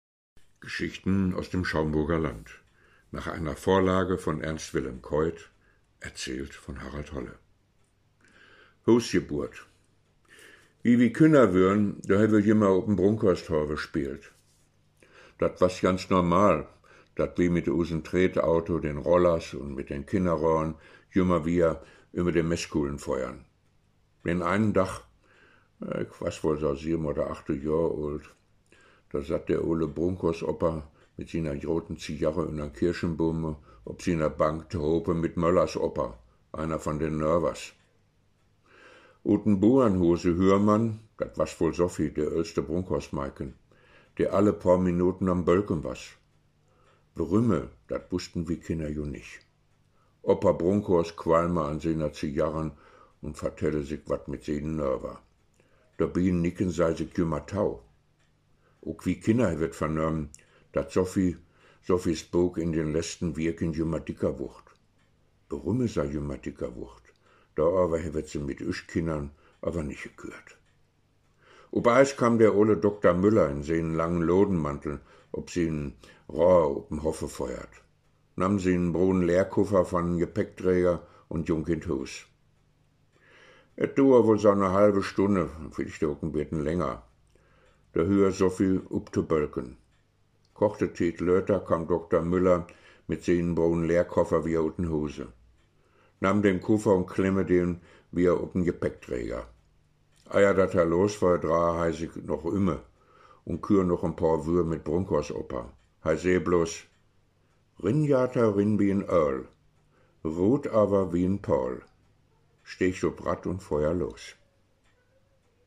Schaumburger Platt